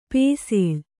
♪ pēsēḷ